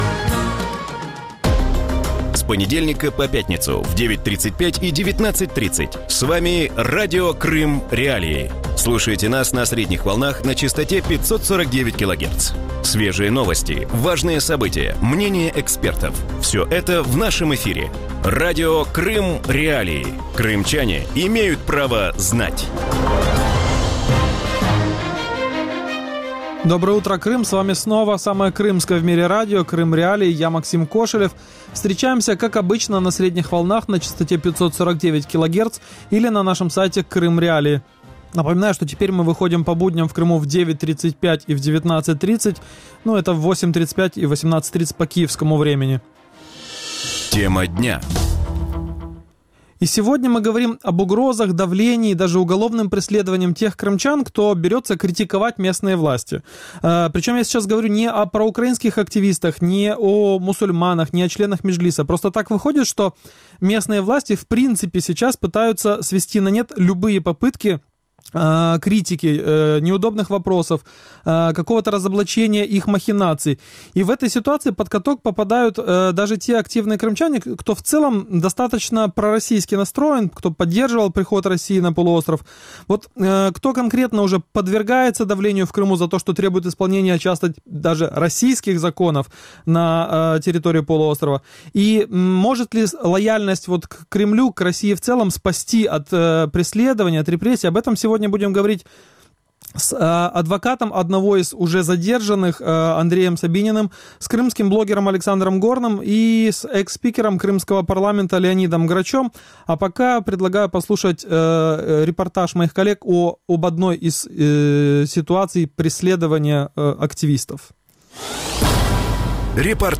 У ранковому ефірі Радіо Крим.Реаліі говорять про проросійських активістів у Криму, які через свою громадську діяльність піддаються кримінальному переслідуванню, а також тиску з боку підконтрольної Росії кримської влади. Що забороняється робити на півострові активним проросійським кримчанам? І хто вже піддався тиску в Криму, відстоюючи російські закони?